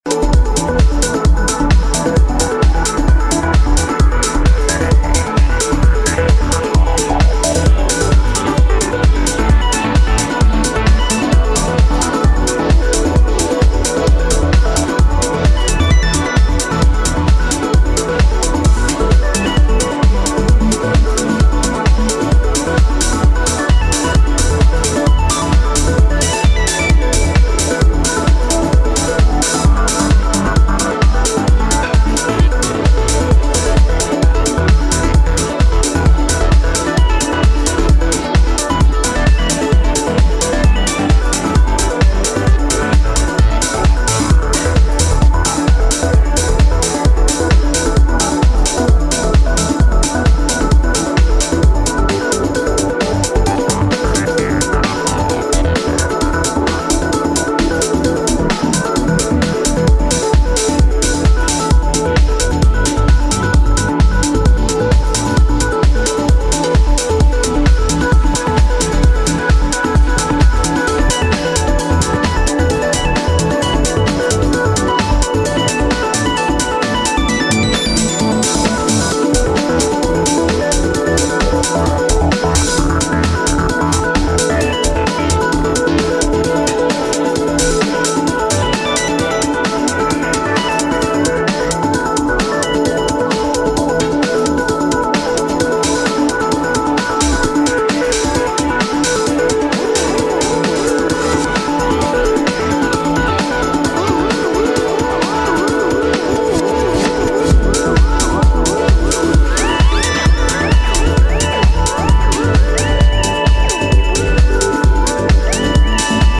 UKプログレッシヴ~ユーロ・トランスラインの夢見心地ユーフォリックなストリングス+煌めくアルペジオがどこまでも上昇する